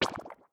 SFX_Slime_Hit_V2_02.wav